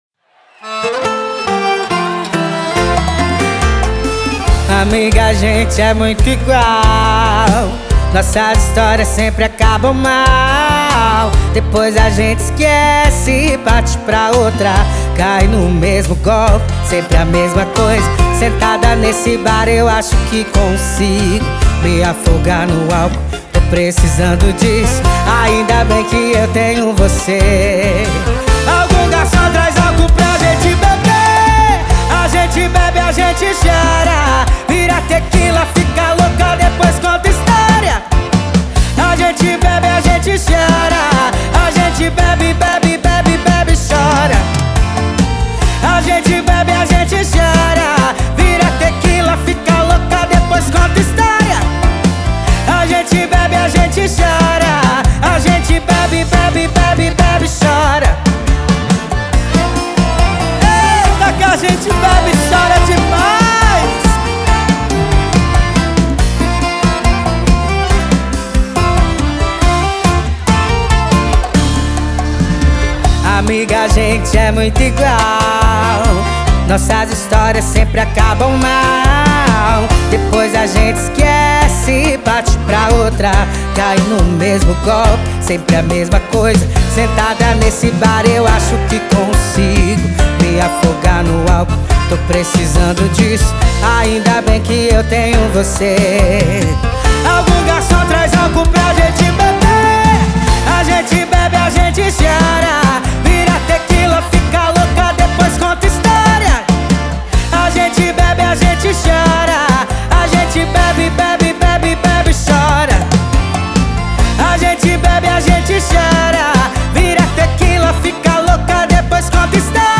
Música – ouvir online e acessar o arquivo